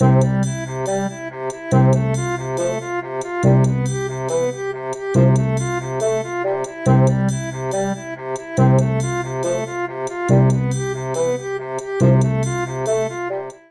Famille : cordes frottées
Il est plus grave que l’alto, et on en joue avec un archet.
Violoncelle